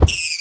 Minecraft Version Minecraft Version 25w18a Latest Release | Latest Snapshot 25w18a / assets / minecraft / sounds / mob / rabbit / bunnymurder.ogg Compare With Compare With Latest Release | Latest Snapshot